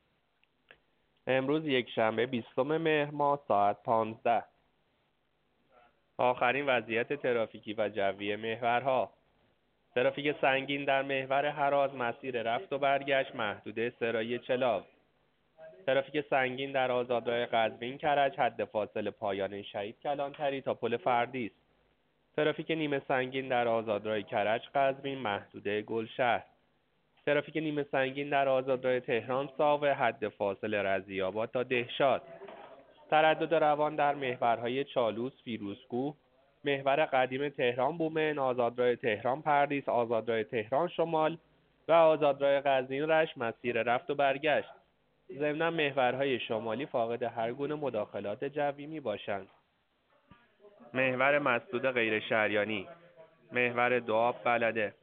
گزارش رادیو اینترنتی از آخرین وضعیت ترافیکی جاده‌ها ساعت ۱۵ بیستم مهر؛